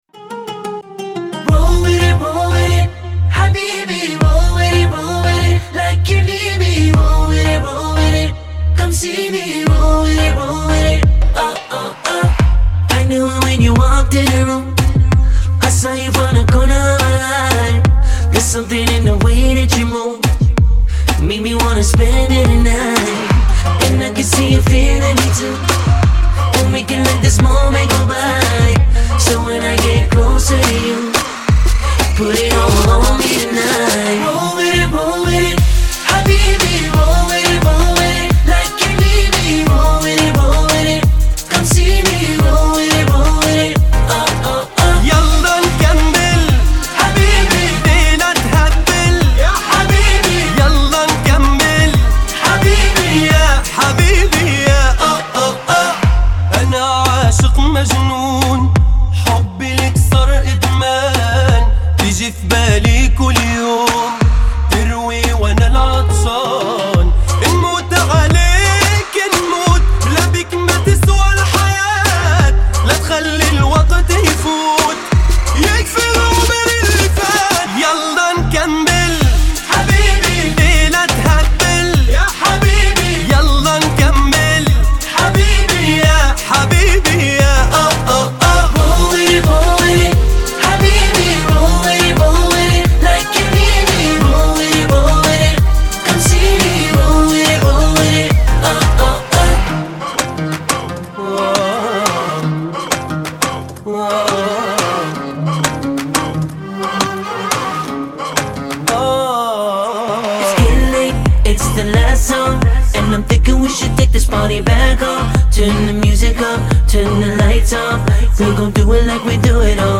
энергичная поп- и R&B-композиция